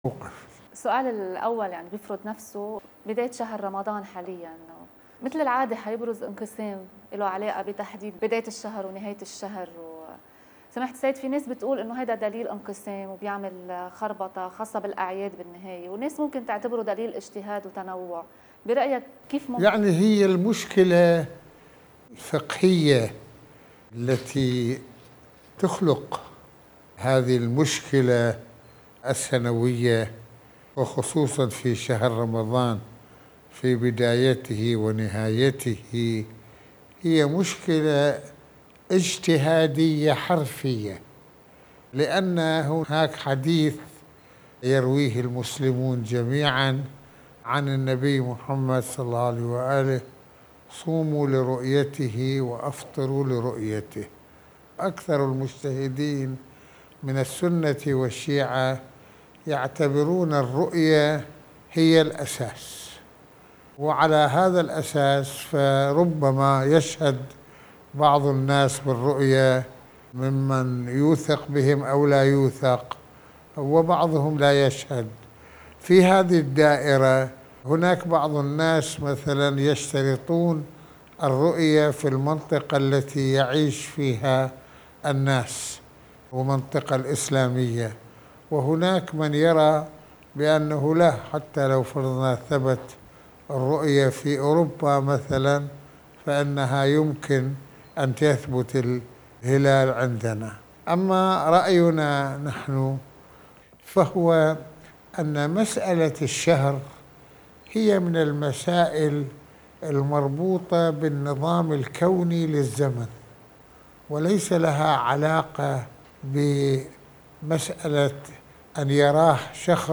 حوار صريح: من الهلال إلى قضايا المرأة والزّواج المدنيّ | مقابلات